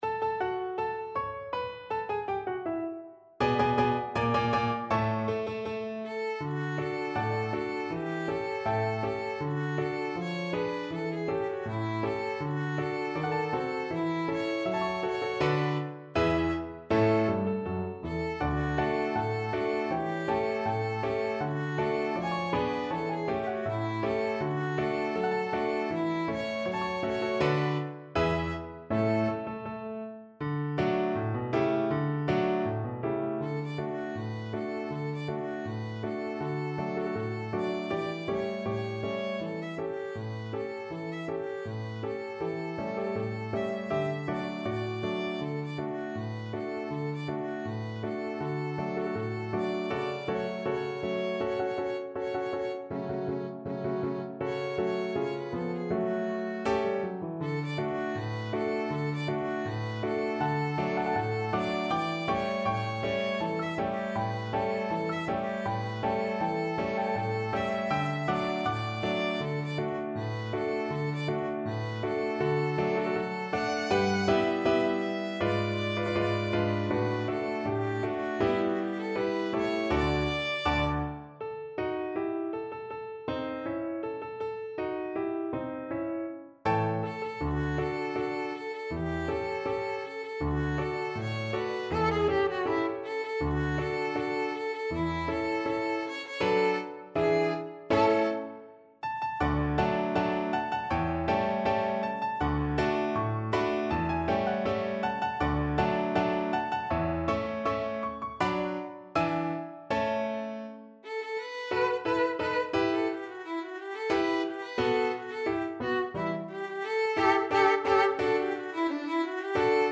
Violin
"The Parade of the Tin Soldiers" (Die Parade der Zinnsoldaten), also known as "The Parade of the Wooden Soldiers", is an instrumental musical character piece, in the form of a popular jaunty march, written by German composer Leon Jessel, in 1897.
D major (Sounding Pitch) (View more D major Music for Violin )
2/2 (View more 2/2 Music)
Not Fast =80
Classical (View more Classical Violin Music)